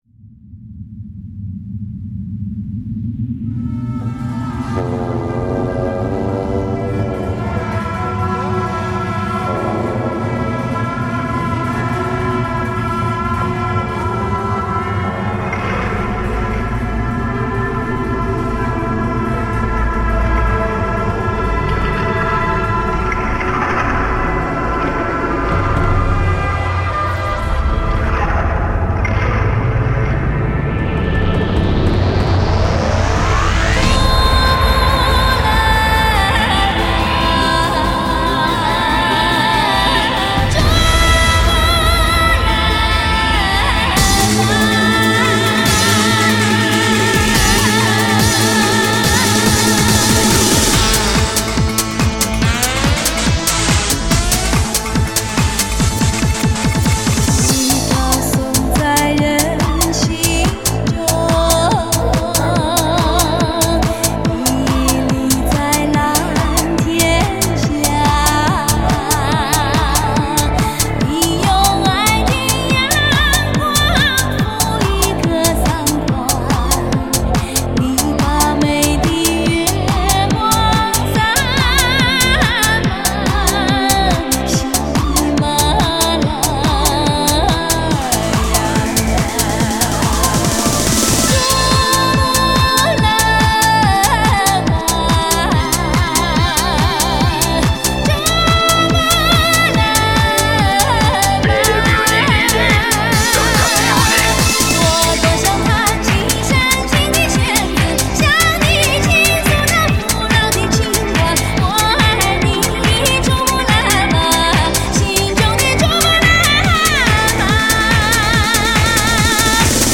藏音迪士高